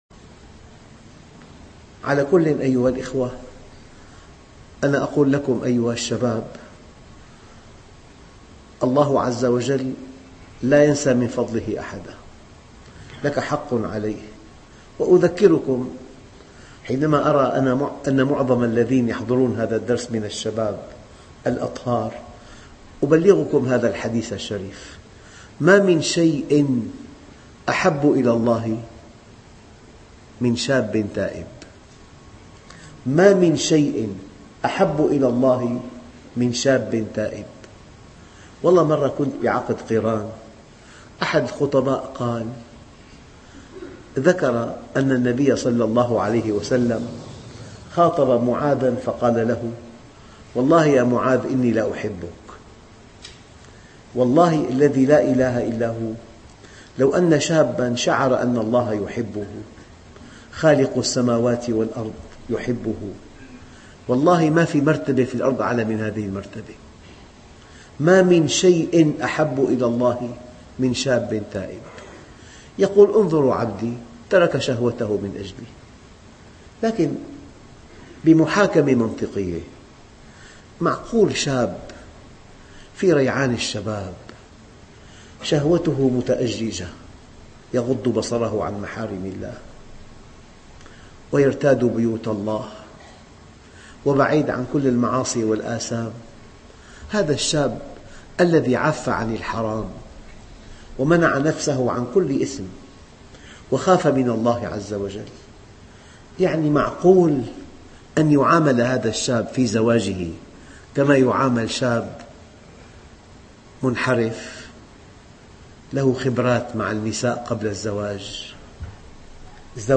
أنا أقول لكم أيها الشباب ... درس مؤثُر ...